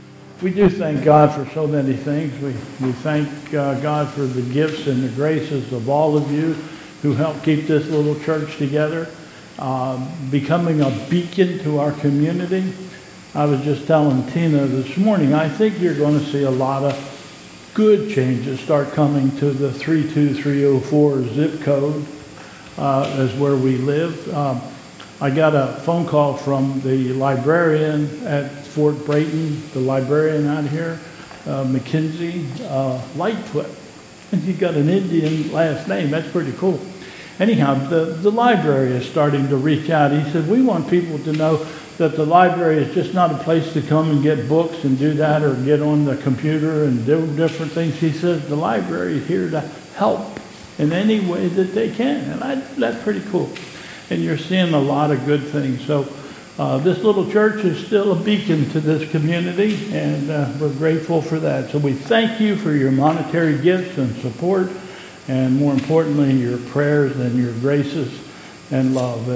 Pastoral prayer and Lord's Prayer